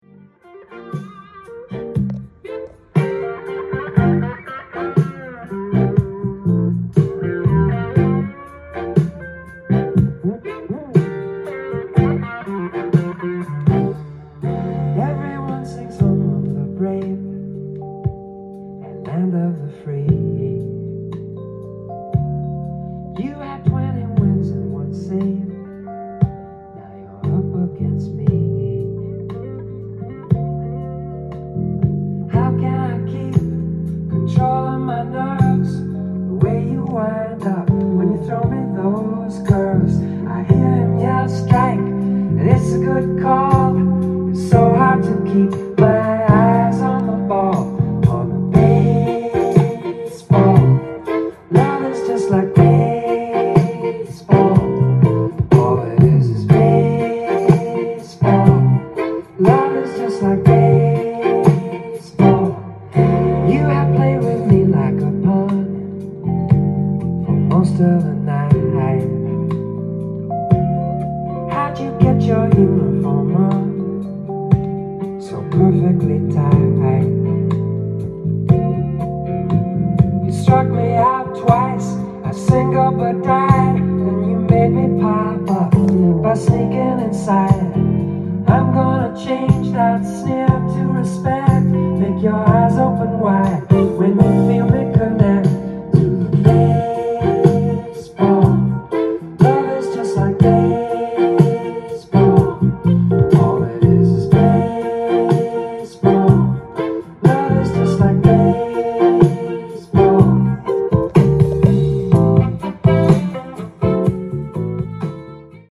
ジャンル：AOR
店頭で録音した音源の為、多少の外部音や音質の悪さはございますが、サンプルとしてご視聴ください。